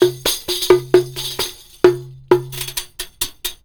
PERC 03.AI.wav